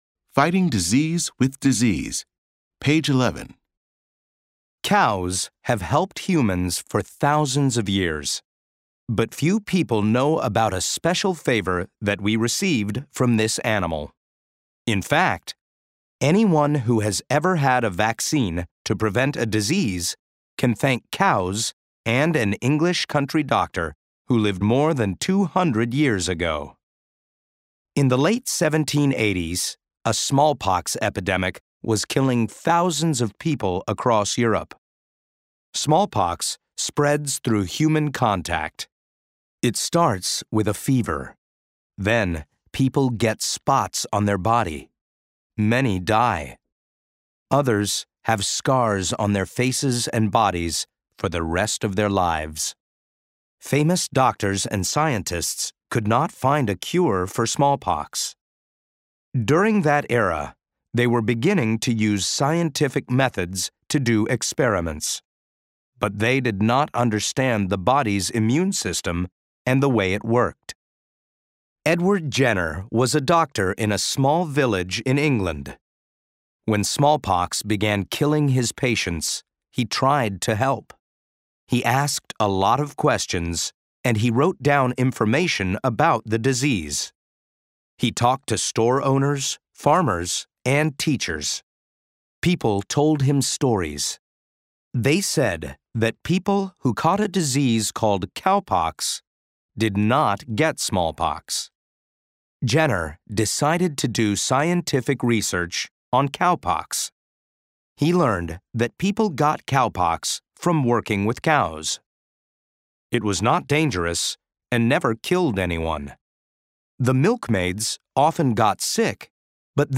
Bound into the back of the book is an audio CD that contains audio recordings of all the stories in the Student's Book.